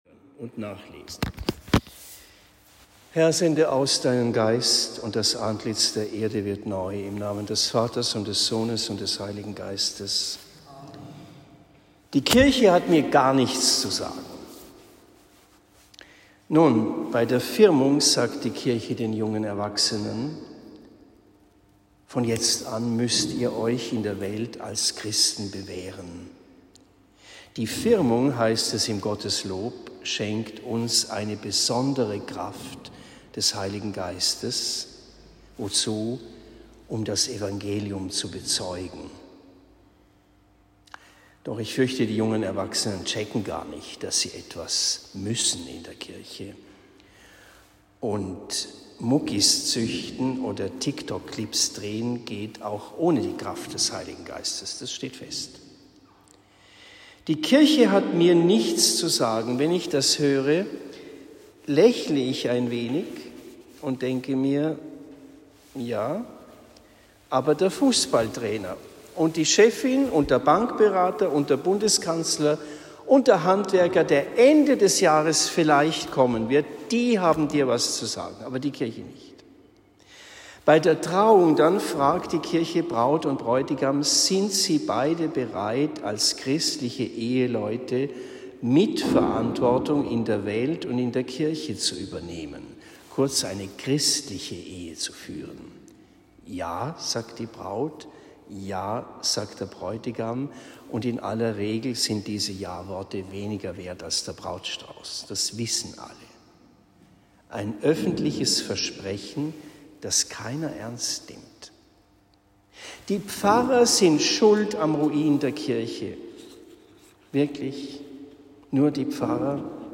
Predigt in Erlenbach am 22. Juni 2023